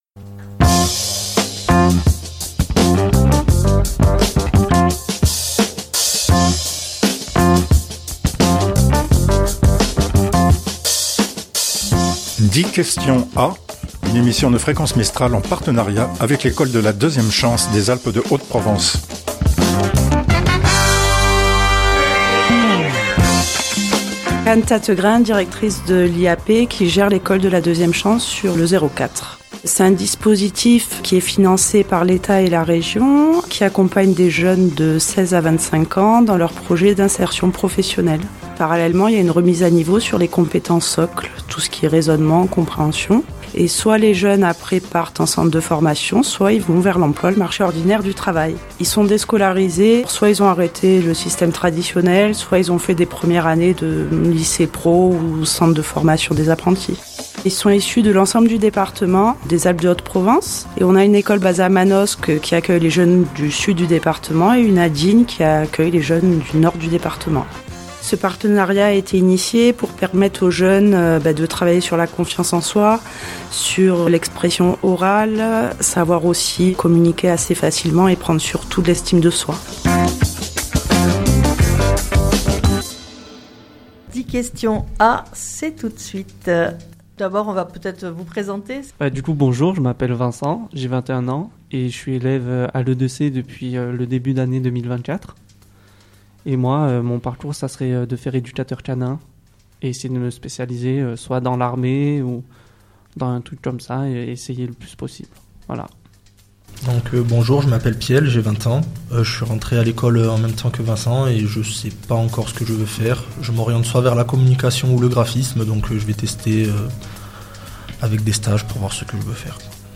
en direct dans le studio de Fréquence Mistral Digne